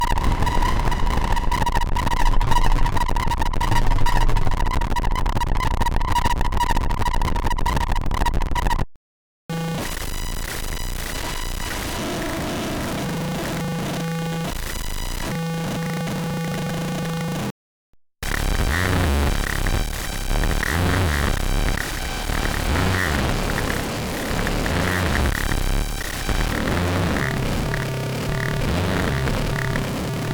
three snippets of broken A4 stuff